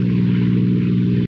bomber1.ogg